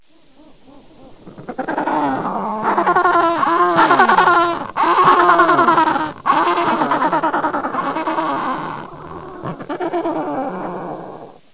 Звуки пингвина
На этой странице вы найдете коллекцию звуков пингвинов: от их забавного «разговора» до шума шагов по льду.